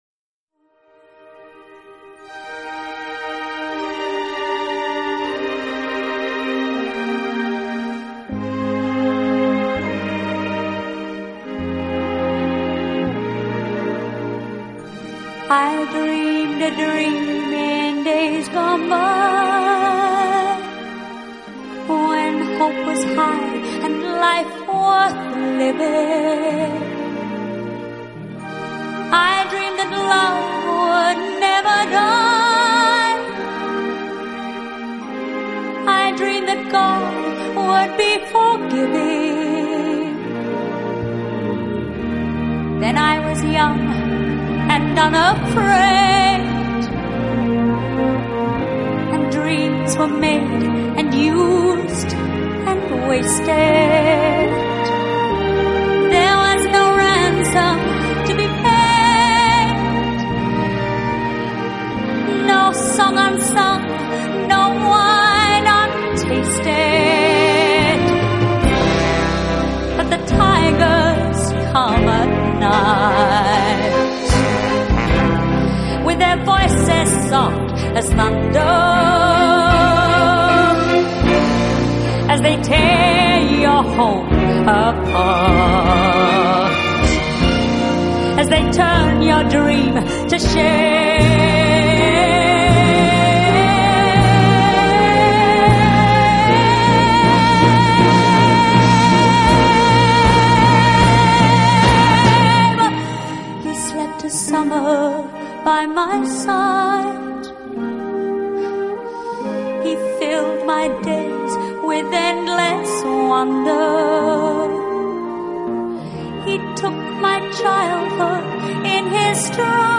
音乐类型：Classical Crossover/Vocal/Instrumental/Easy Listening